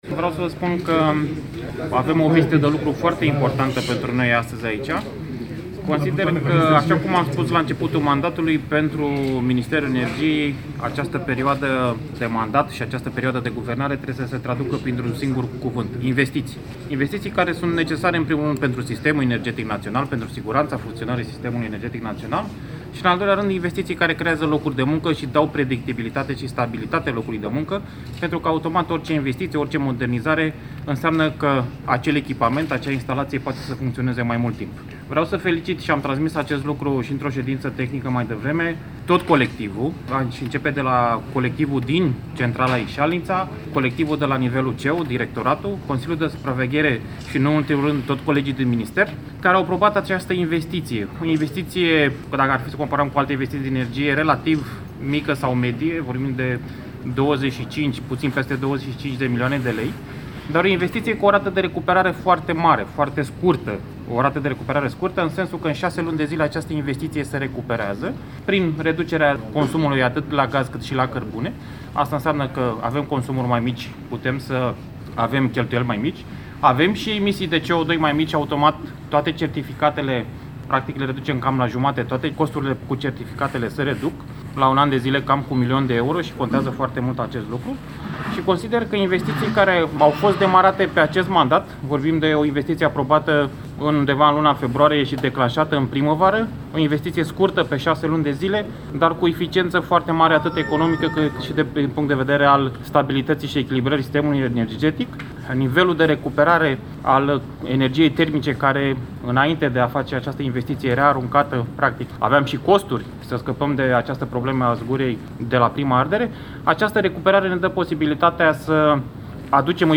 Ministrul Energiei, Toma Petcu, declaratie la Termocentrala de la Isalnita